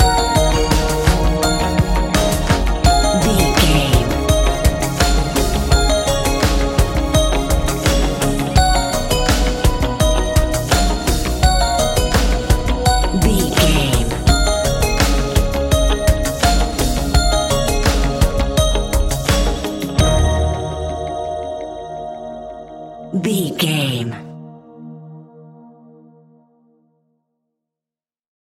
Ionian/Major
D♯
electronic
dance
techno
trance
synths
synthwave
instrumentals